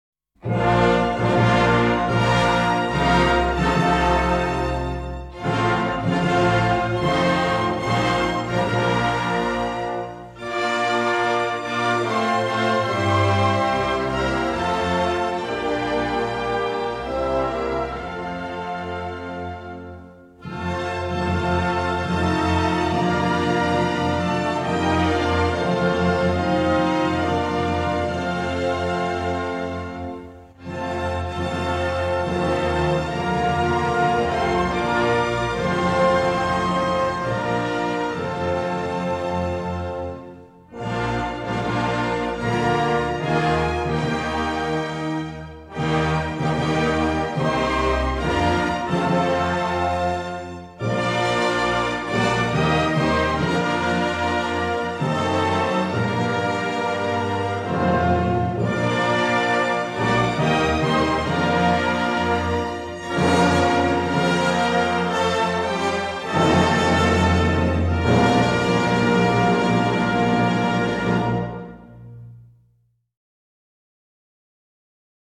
Cantique Suisse. Instrumental.
Orchestre de la Suisse Romande.